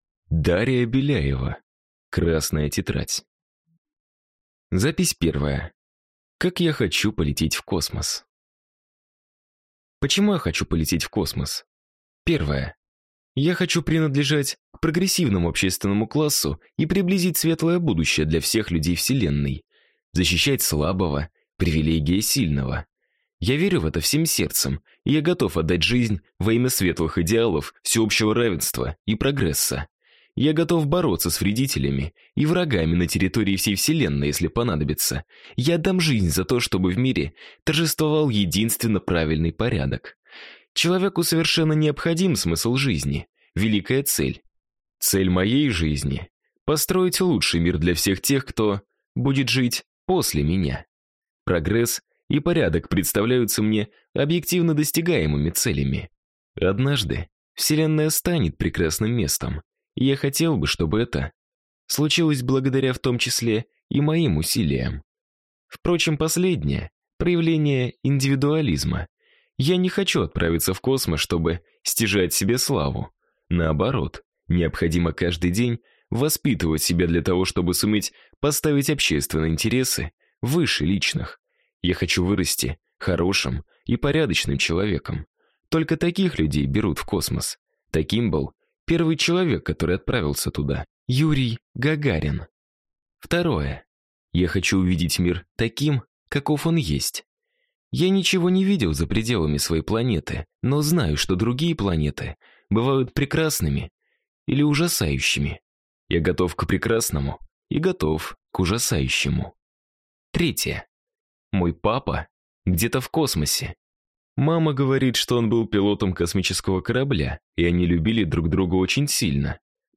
Аудиокнига Красная тетрадь | Библиотека аудиокниг
Прослушать и бесплатно скачать фрагмент аудиокниги